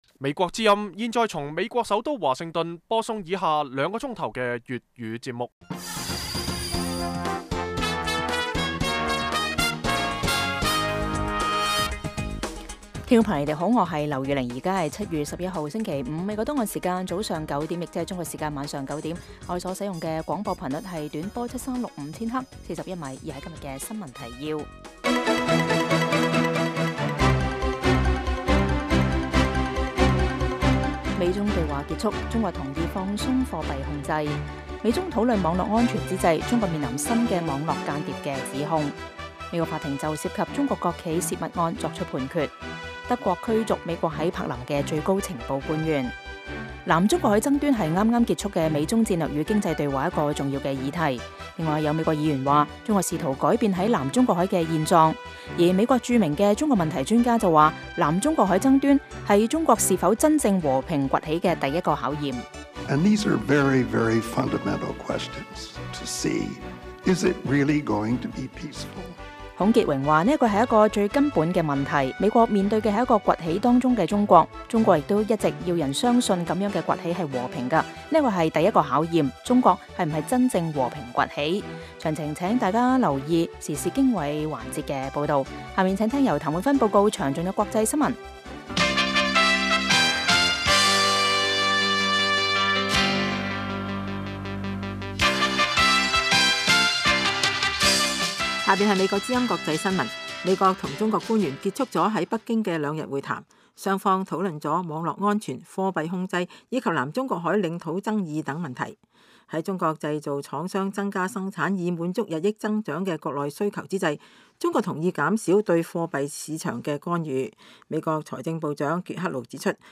粤语新闻 晚上9-10点
每晚 9點至10點 (1300-1400 UTC)粵語廣播，內容包括簡要新聞、記者報導和簡短專題。